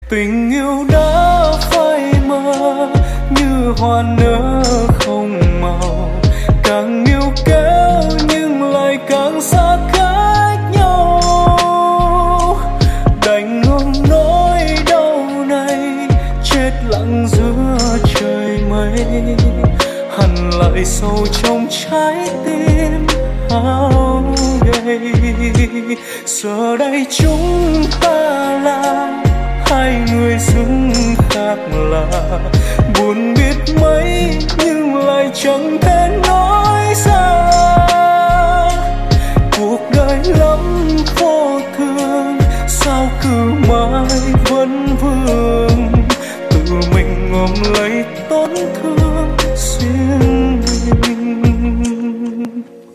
Lofi